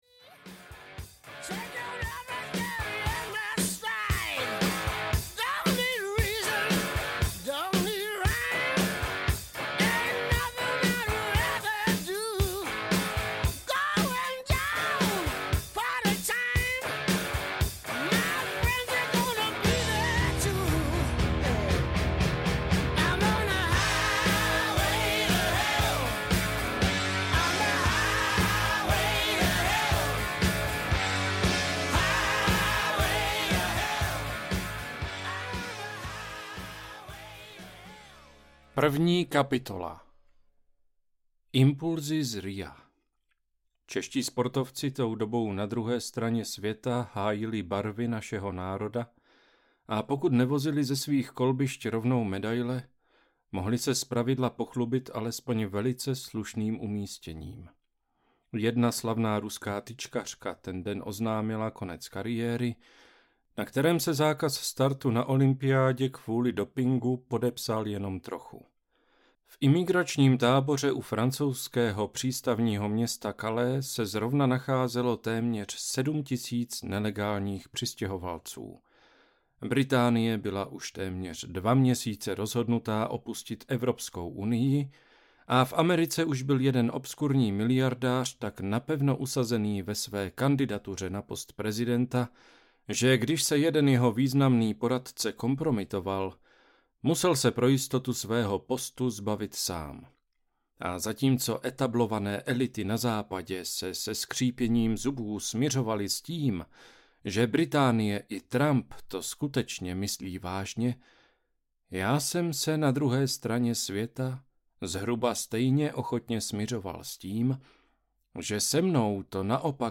Promrhaná něha audiokniha
Ukázka z knihy